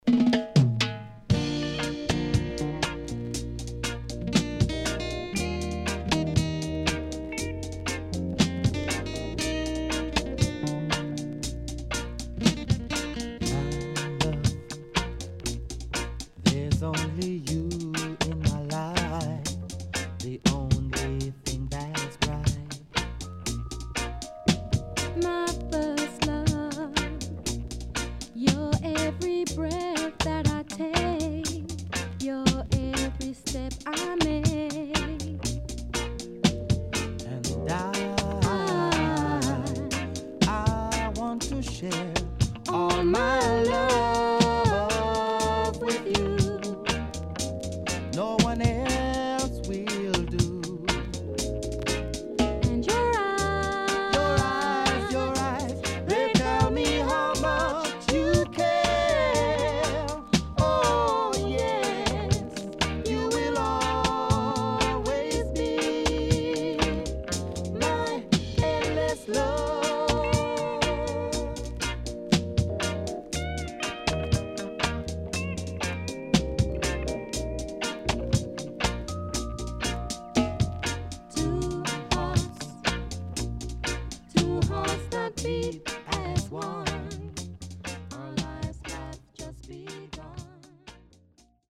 【12inch】
SIDE B:少しチリノイズ入りますが良好です。